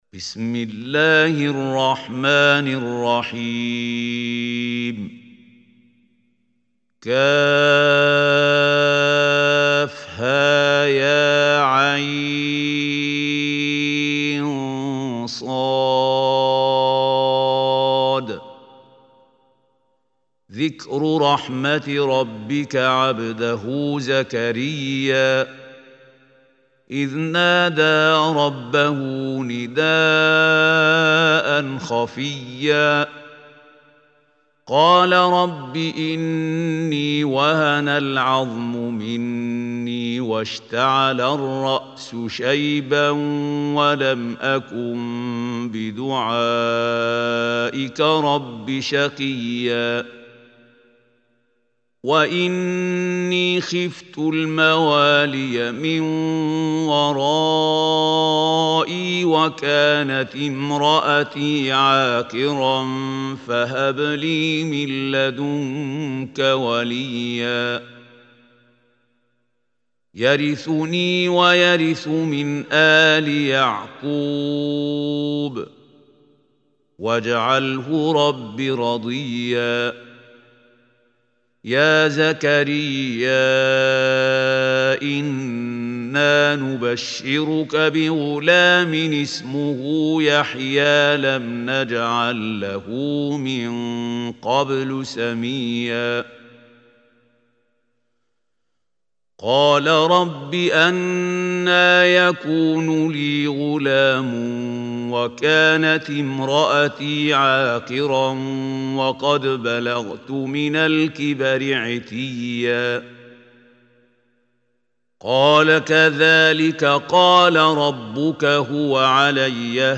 Surah Maryam Recitation by Mahmoud al Hussary
Listen online and download beautiful tilawat / recitation of Surah Maryam in the beautiful voice of Qari Mahmoud Khalil Al Hussary.
surah-maryam.mp3